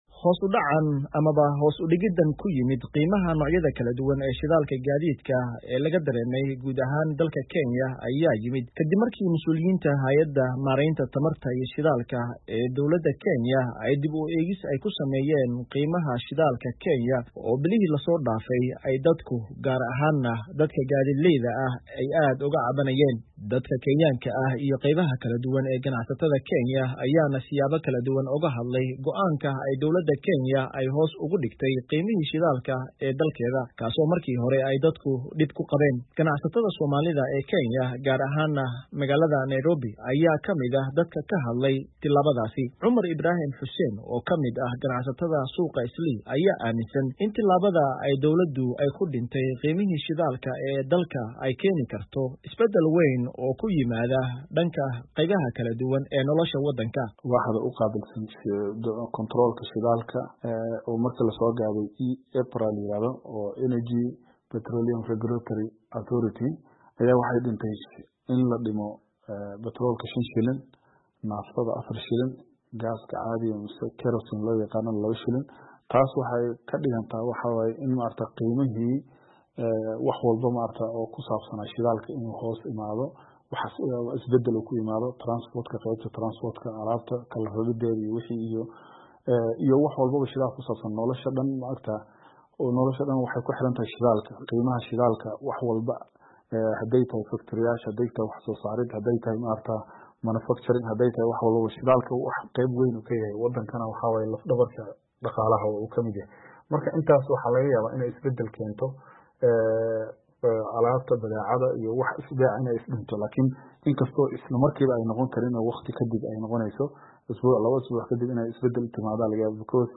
Haddaba ganacstada Soomaalida ee Kenya ayaana ka hadlay sida ay u arkaan arrintaas.